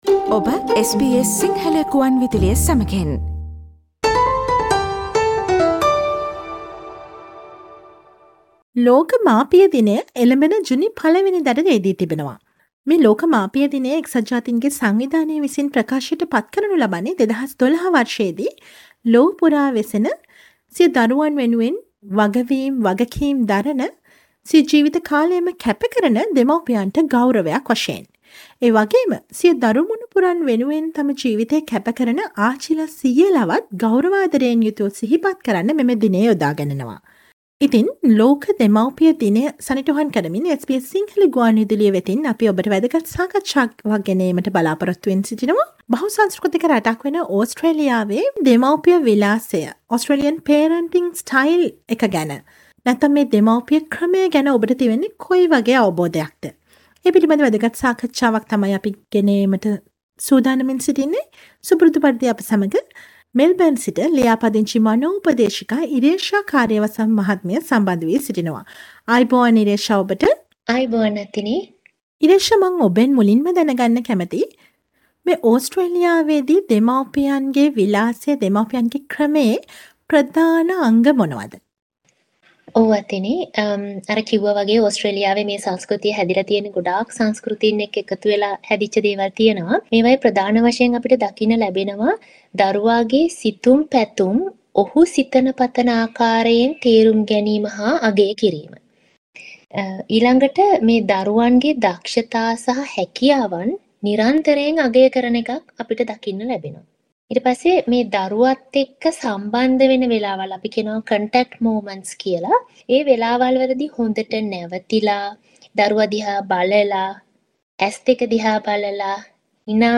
SBS සිංහල ගුවන්විදුලි සේවය සිදු කළ සාකච්ඡාව